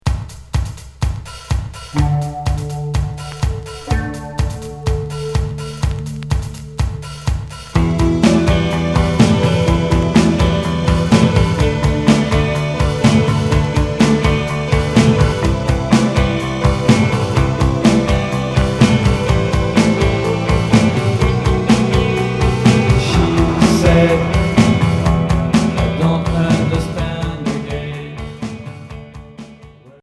Cold rock